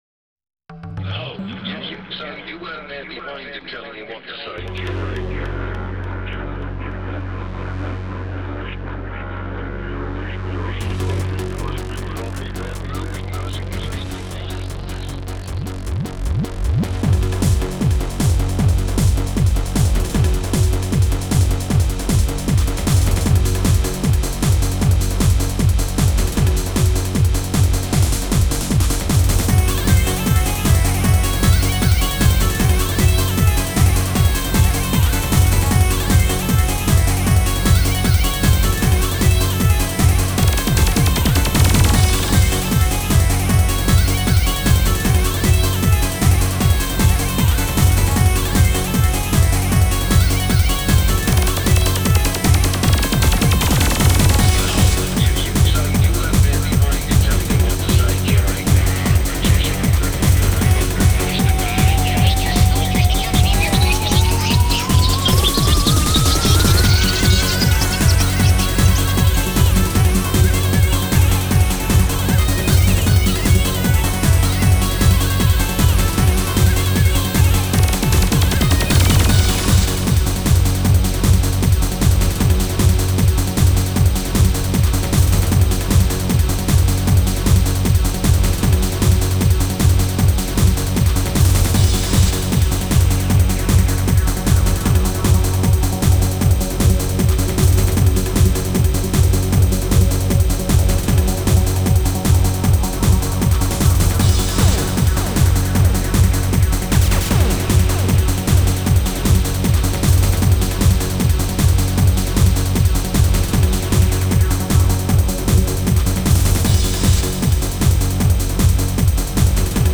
Style: Psy Trance